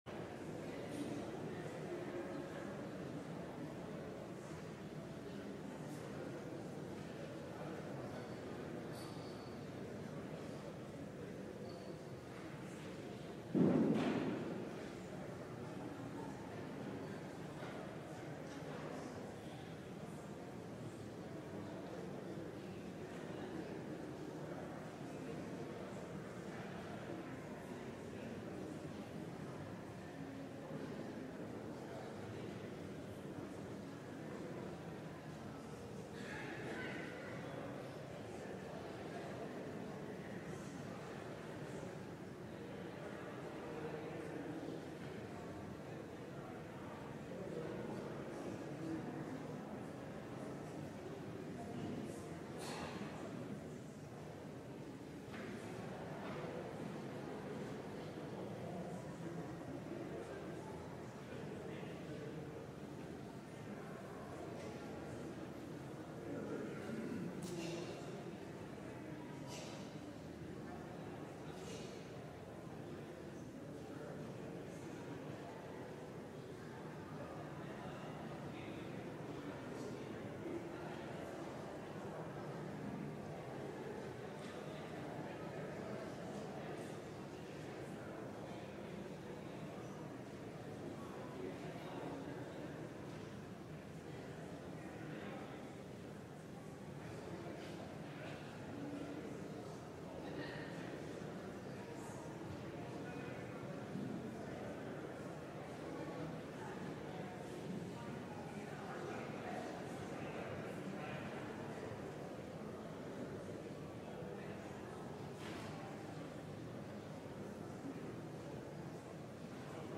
LIVE Morning Worship Service - A Rhythm as Old as the World
Congregational singing—of both traditional hymns and newer ones—is typically supported by our pipe organ.